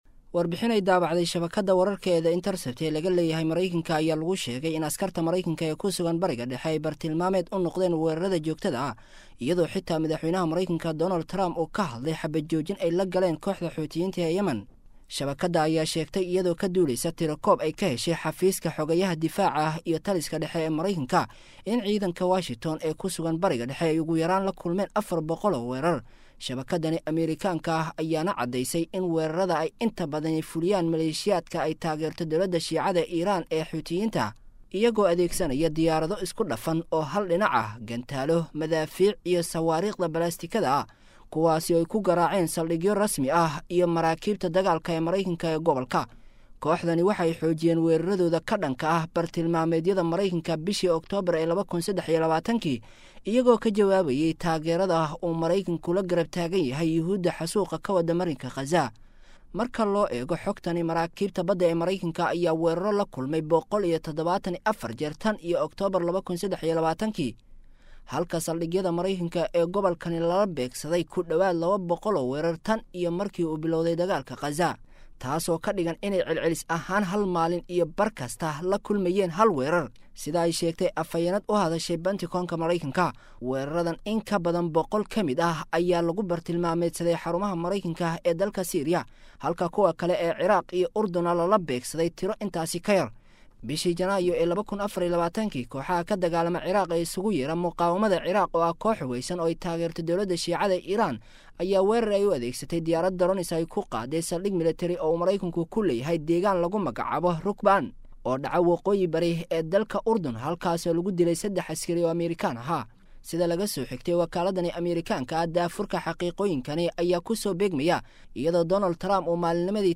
The Intercept: Ameerikaanka ku Sugan Bariga-dhexe Waxay Maalin Waliba la Kulmaan Weerar.[WARBIXIN]